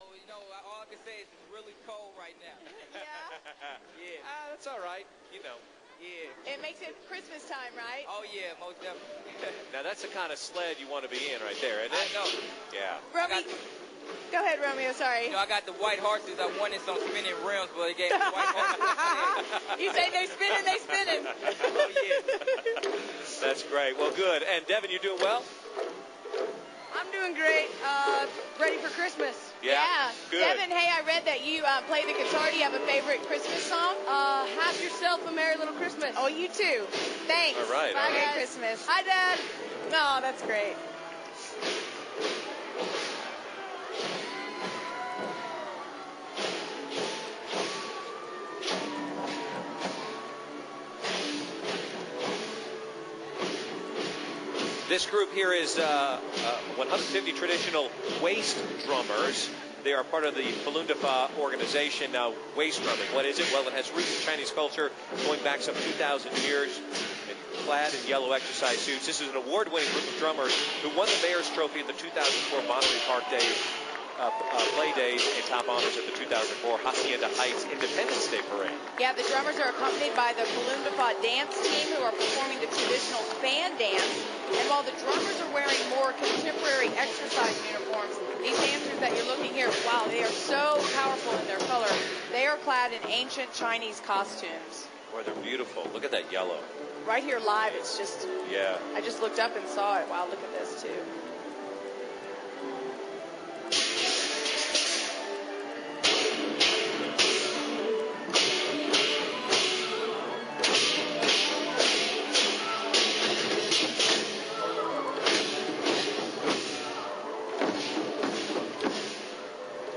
Hollywood Christmas Parade
2005hollywoodxmasparade.rm